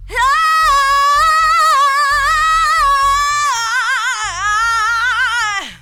AHH AHH AHH.wav